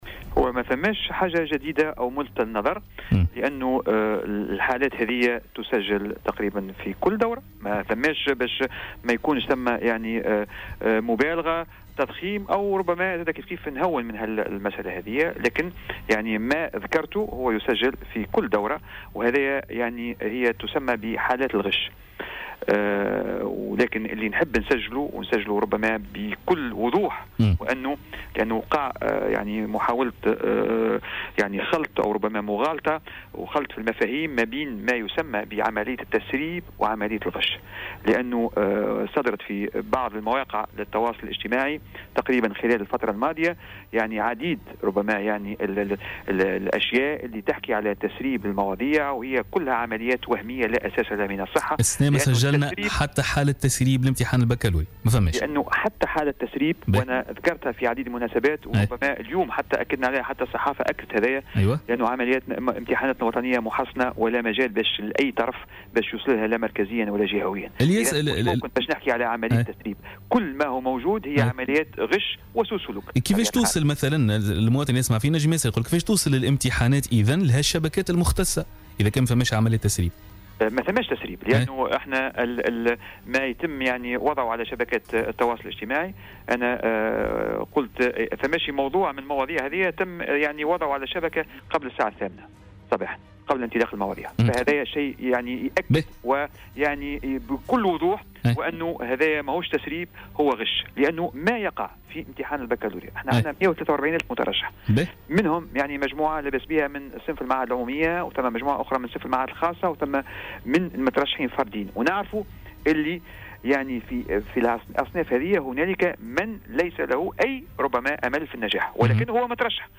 أكد مدير عام الامتحانات بوزارة التربية، عمر الولباني في مداخلة له اليوم الثلاثاء في برنامج "بوليتيكا" عدم تسريب أي موضوع من امتحانات الباكالوريا لهذه السنة.